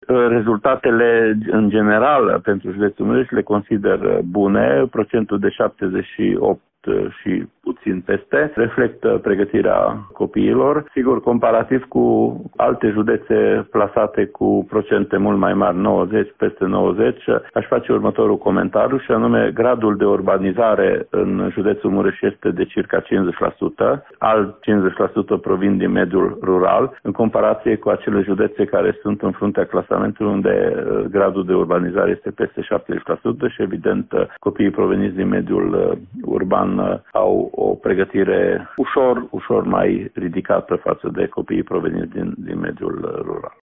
Inspectorul școlar general Ștefan Someșan.